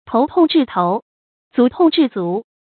tóu tòng zhì tóu，zú tòng zhì zú
头痛治头，足痛治足发音